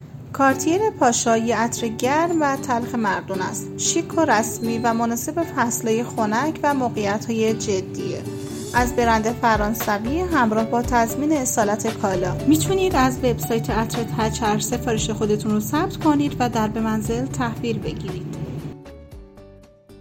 • معرفی عطر توسط کارشناس